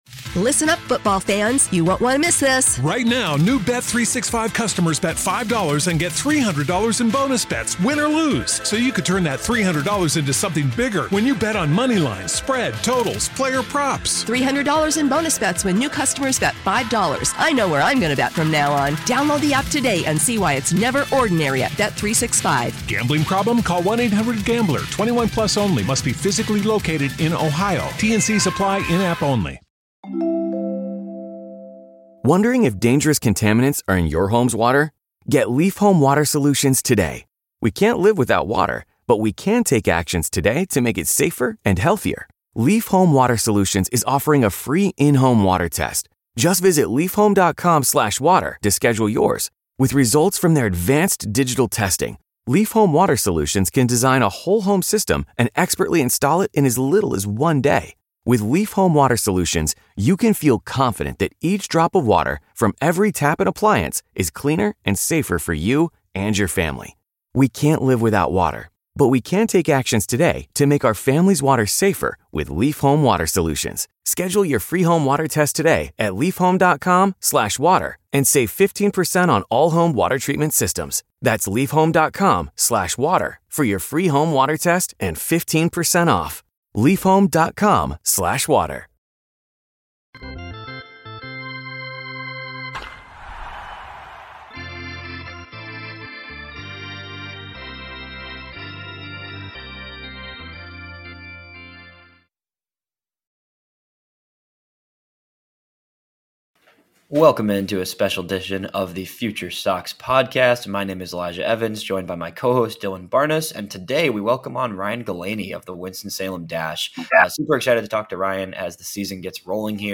FutureSox Interview Ft.